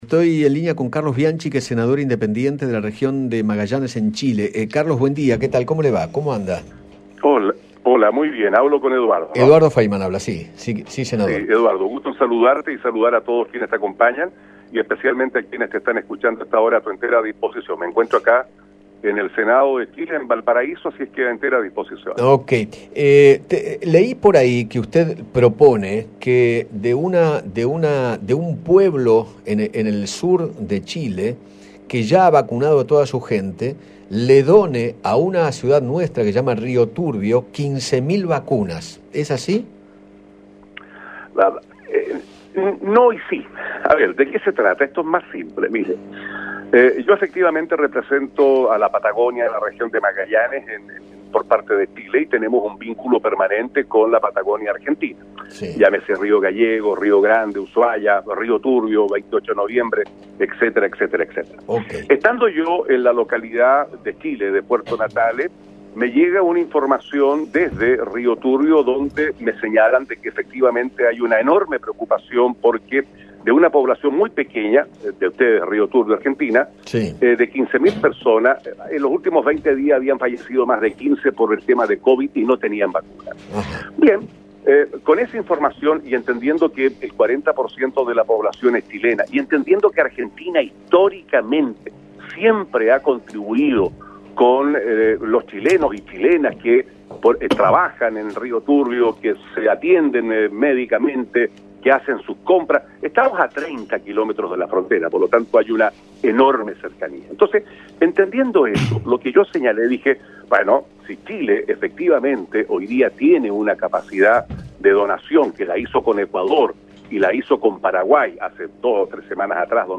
Carlos Bianchi, senador nacional de Chile, dialogó con Eduardo Feinmann y le contó que Puerto Natales, donde ya se vacunó a toda la población de riesgo, desea regalarle las dosis que le quedaron a la localidad de Río Turbio, en Santa Cruz.